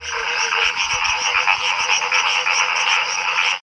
After heavy rain, several species of frog and toad may come out at once, creating an absolute pandemonium of sound, as the croaking of Hyla loquax, the quacking of Hyla microcephala, the chirping of Hyla picta, the grinding of Bufo valliceps, the gurgling of Rana berlandieri, the chattering of Agalychnis moreletii, the honking of Smilisca baudinii and the wailing of Rhinophrynus dorsalis all compete to be heard.
Play soundClick here for the sound of frogs at one of the ponds
pond.mp3